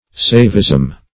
Saivism \Sai"vism\, n. The worship of Siva.